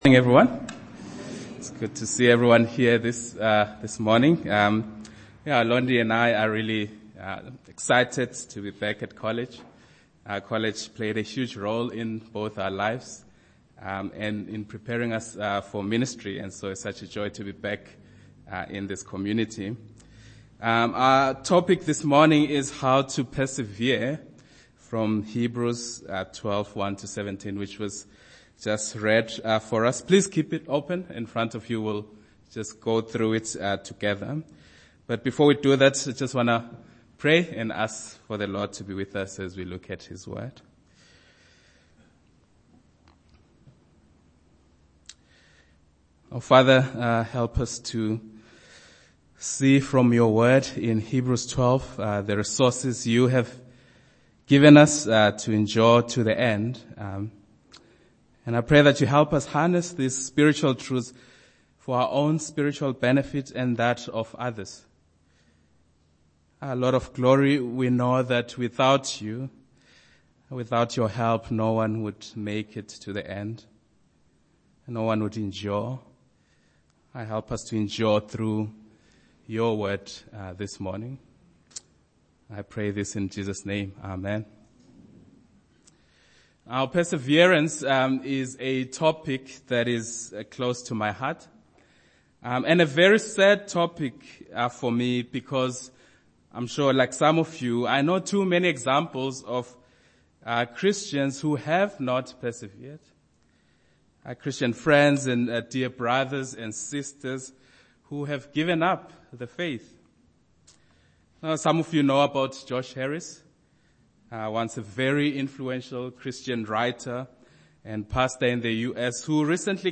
Chapel Sermon